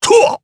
Phillop-Vox_Jump_jp_b.wav